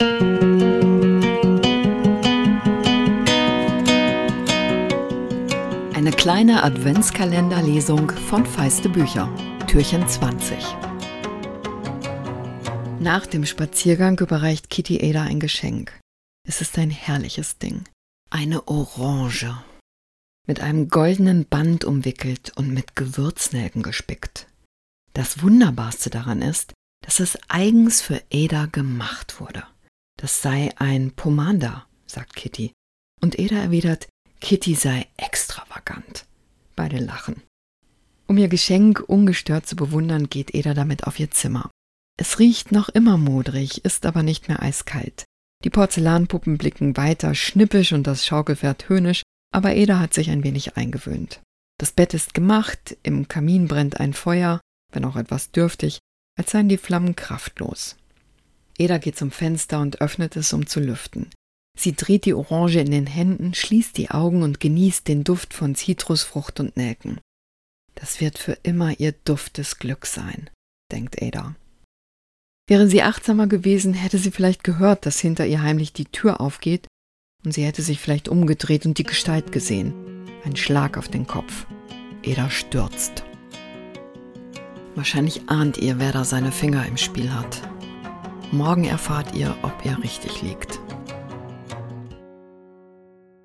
Adventskalender-Lesung 2024!